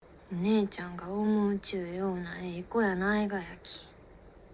由於柳瀬小時候在四國的高知長大，因此本劇大部分角色皆使用當地方言——土佐方言。